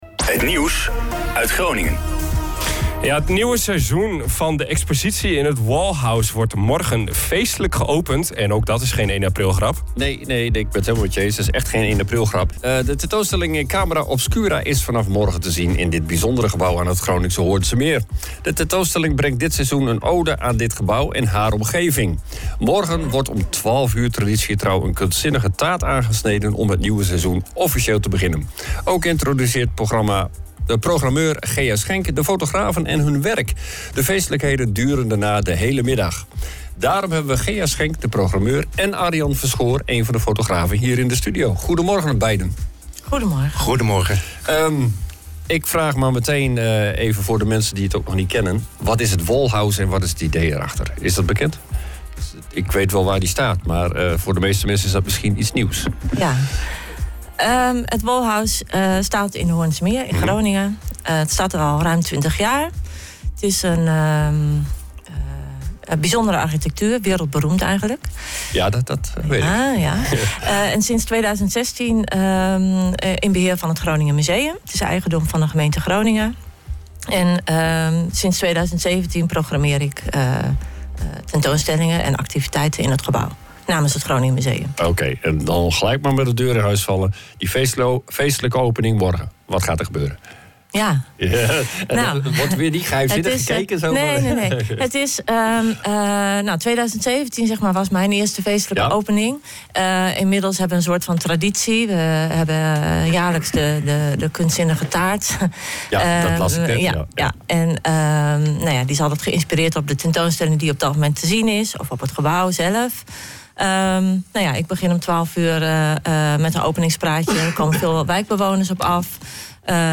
waren te gast in de OOG Ochtendshow